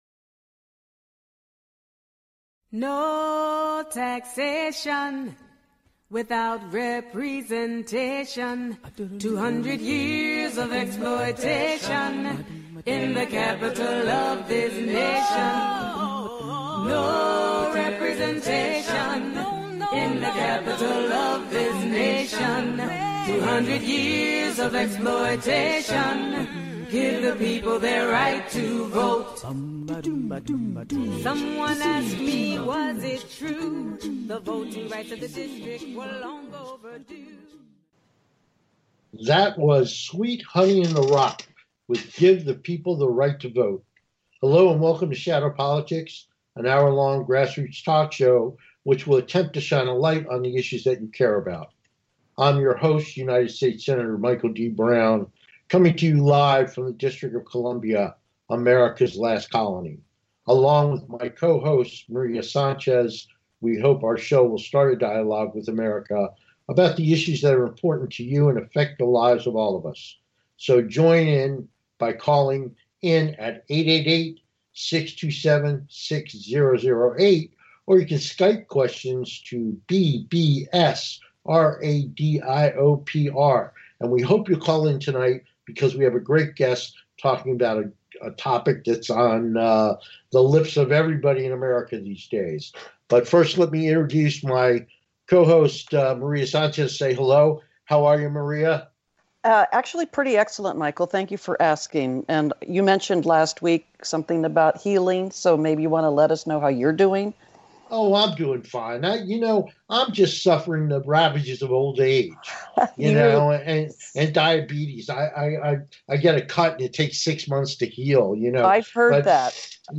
Talk Show Episode, Audio Podcast, Shadow Politics and Current Affairs on , show guests , about Current Affairs,Trump and Pelosi,government shutdown,Presidential Candidate 2020, categorized as Entertainment,History,Kids & Family,News,Politics & Government,Self Help,Society and Culture,Variety